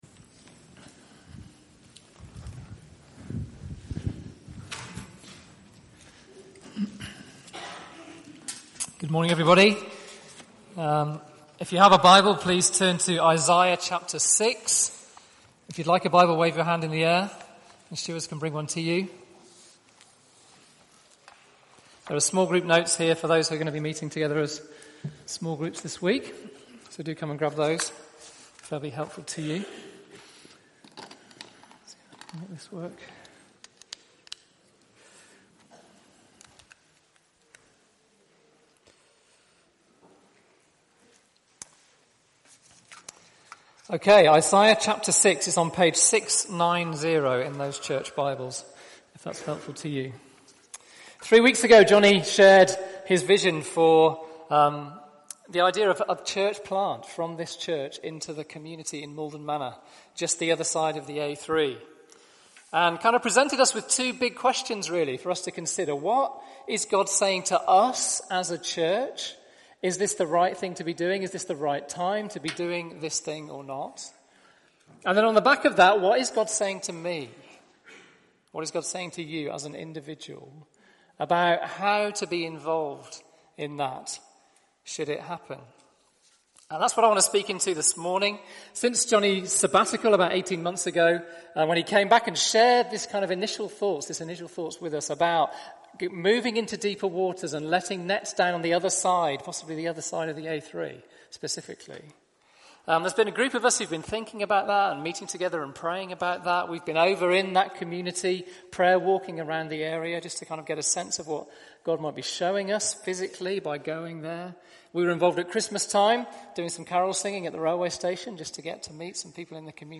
Media for Sunday Service on Sun 20th Nov 2016 10:30 Speaker
General Theme: Church Planting - Who will go for us? Sermon HINT: Try searching for part of a speaker's name, bible reference or series title.